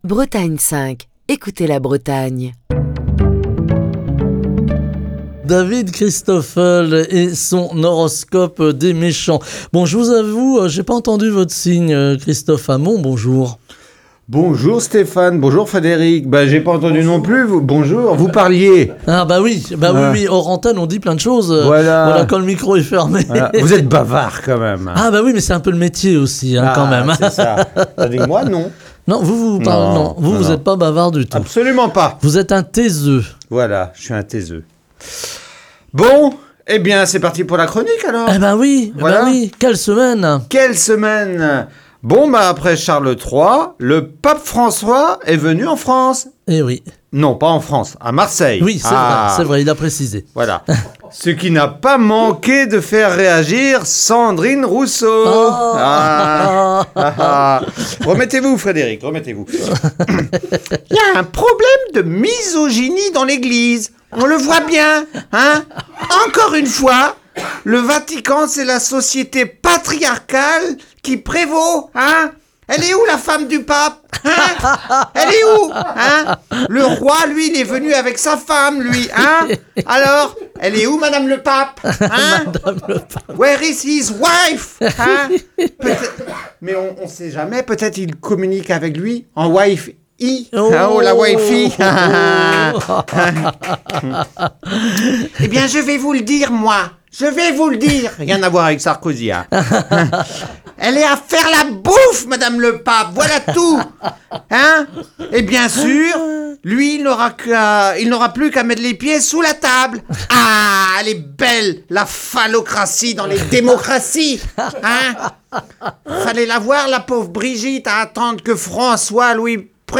Chronique du 29 septembre 2023.